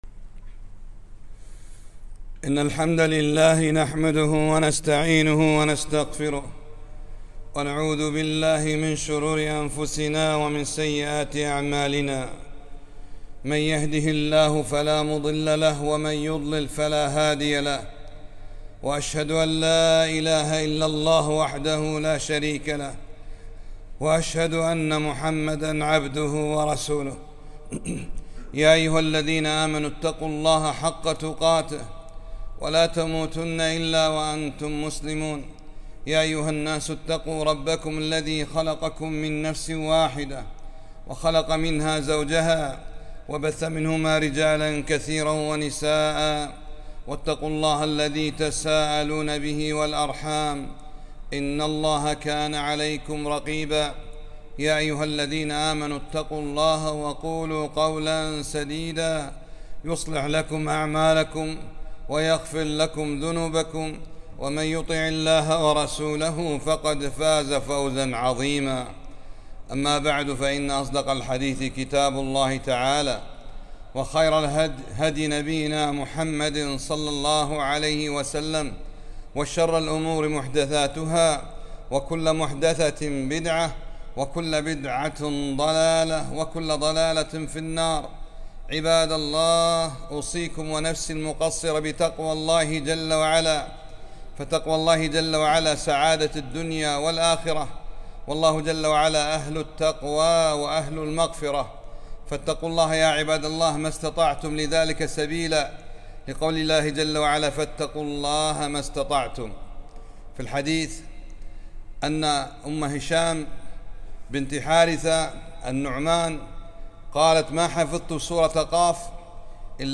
خطبة - سورة (ق) معاني عظيمة فاتعظوا ياعباد الله - دروس الكويت